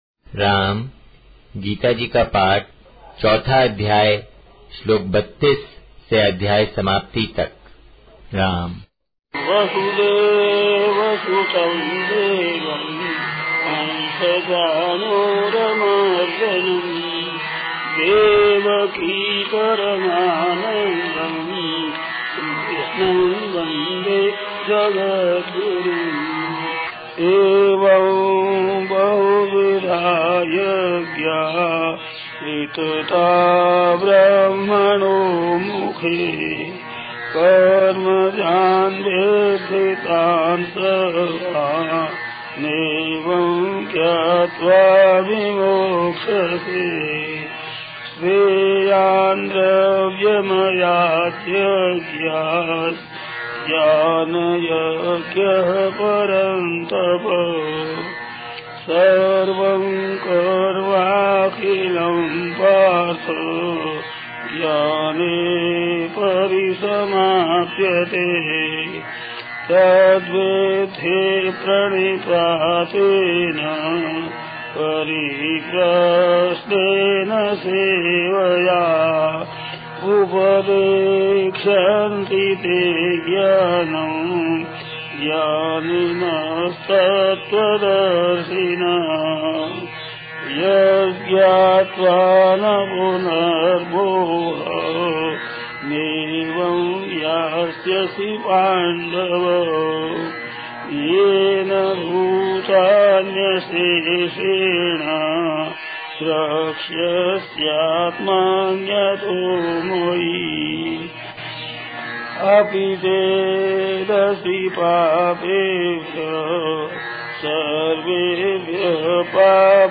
Welcome to Audio - Gita Paath in Sanskrit